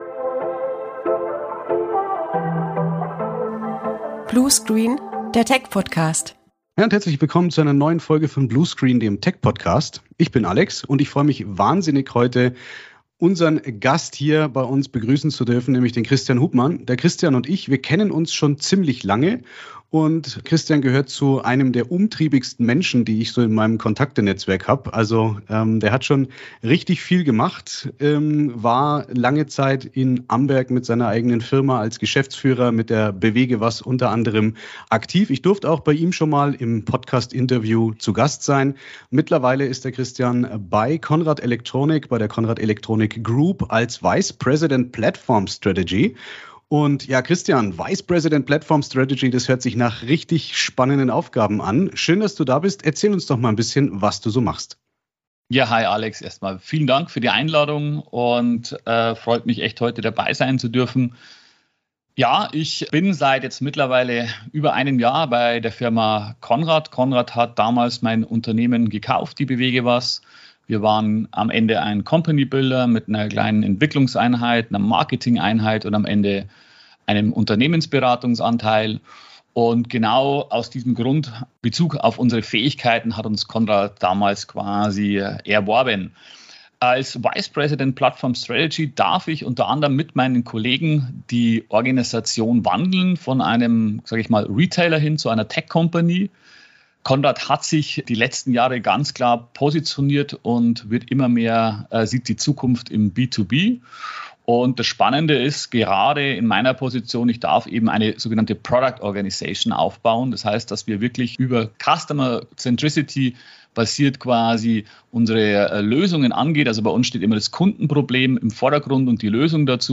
Folge 25 von Bluescreen - Der Tech-Podcast! Im Interview